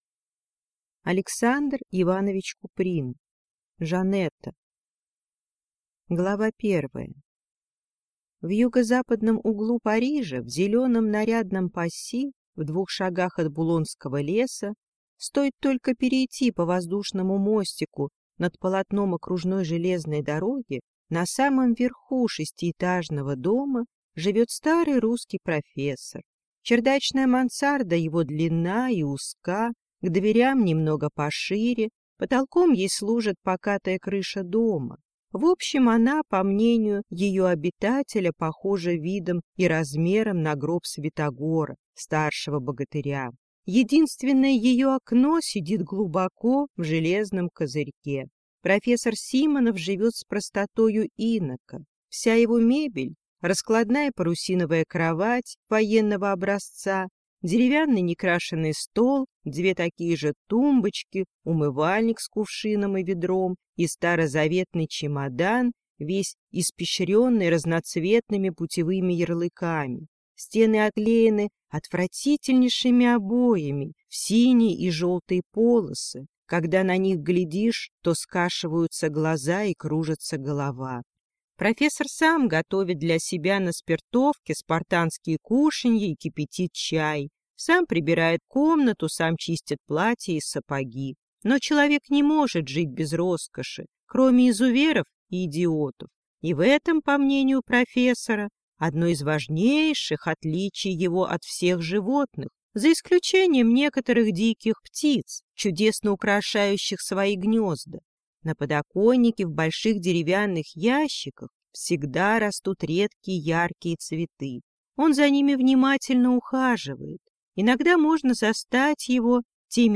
Aудиокнига Жанета